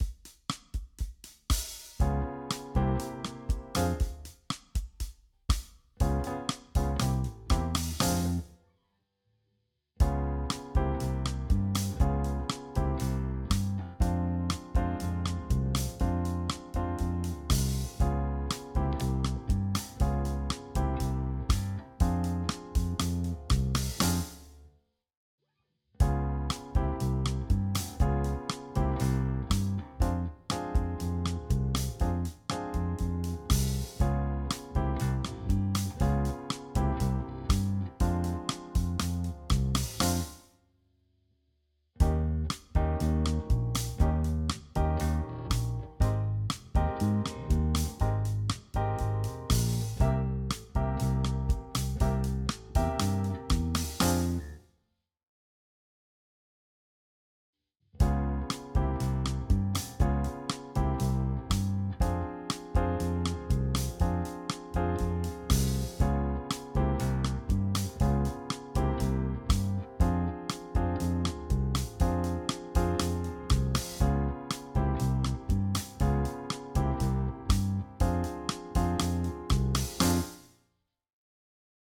Jam Track
Cover version